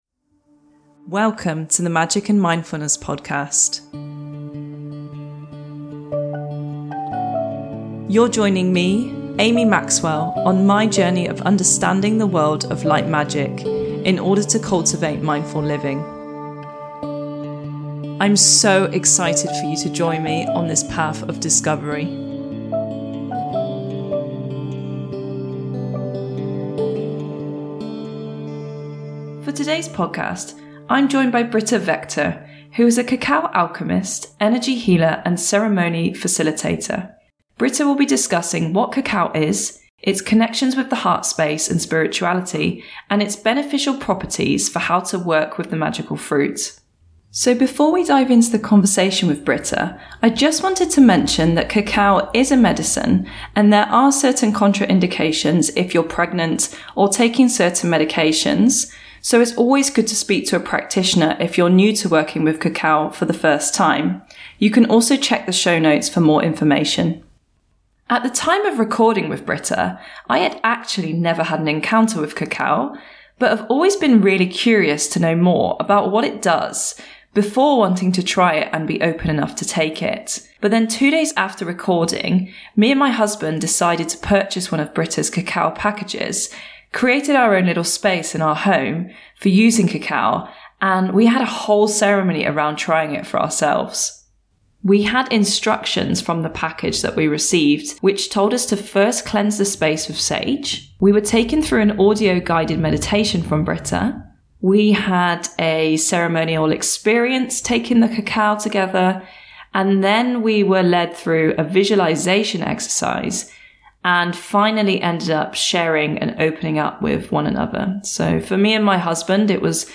During this conversation